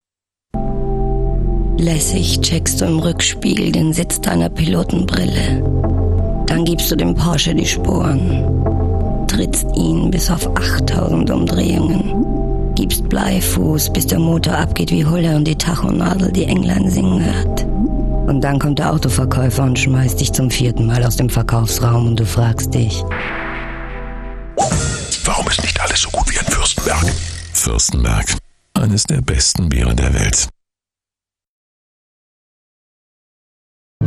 fuerstenberg_werbung4.mp3